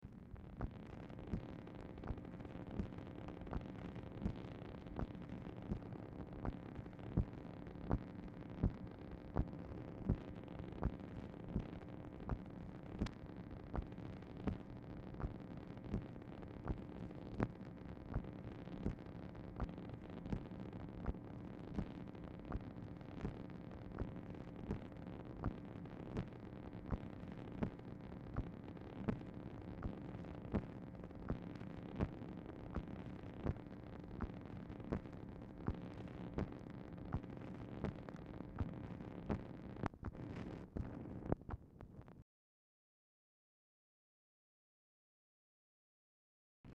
Telephone conversation # 4841, sound recording, MACHINE NOISE, 8/9/1964, time unknown | Discover LBJ
Format Dictation belt
Specific Item Type Telephone conversation